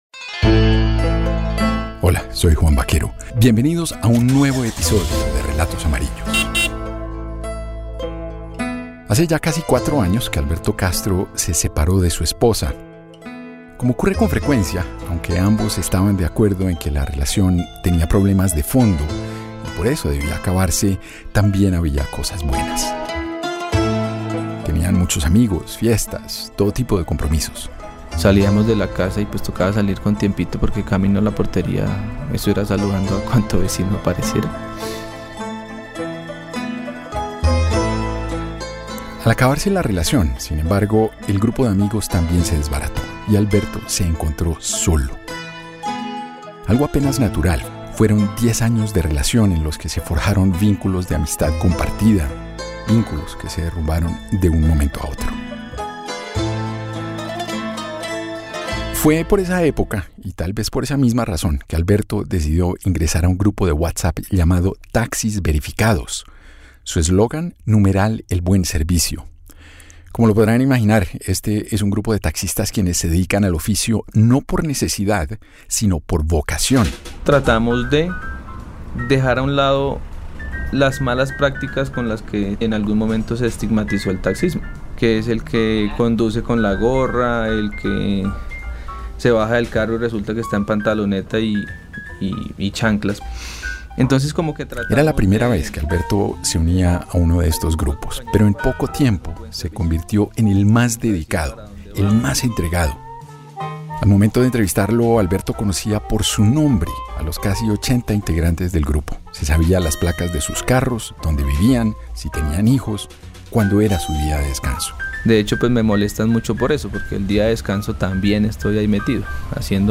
Podcast narrativos en español.
Tres taxistas nos hablan sobre la importancia de la familia.